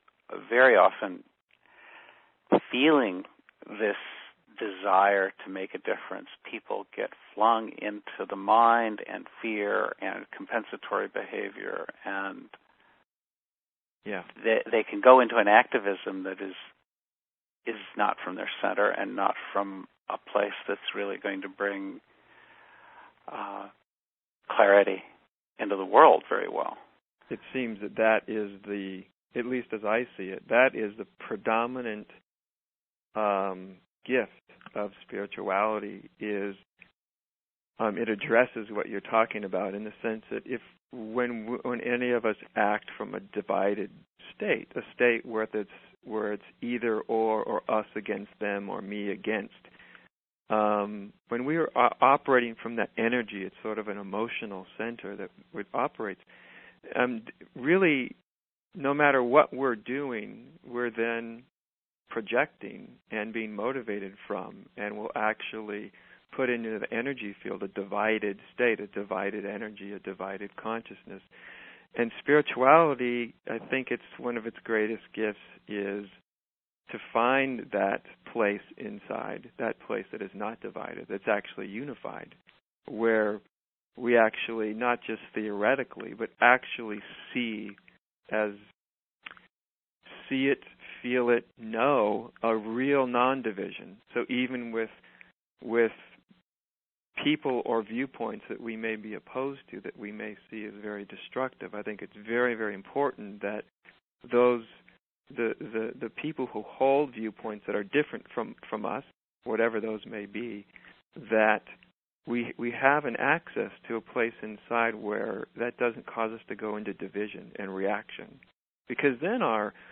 Going Beyond “Re-activism” — from a dialogue with Adyashanti